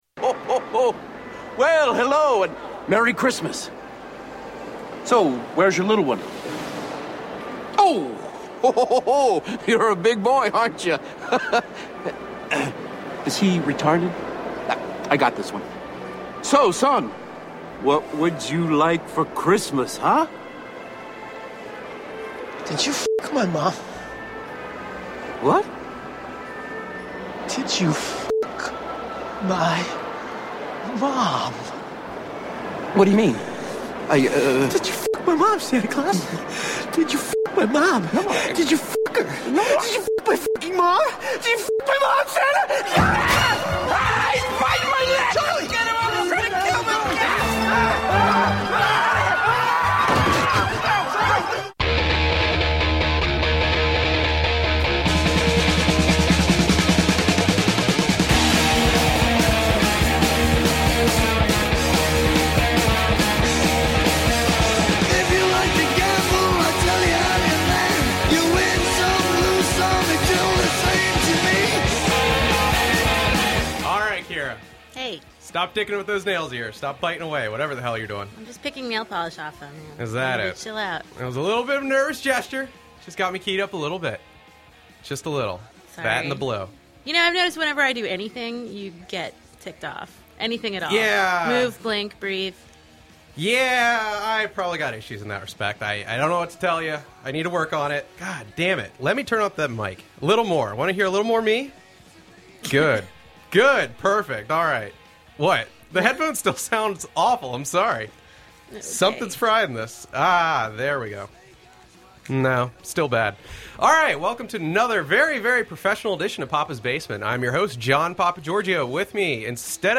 who reveals himself to be an incredibly nice guy and a really fun interview.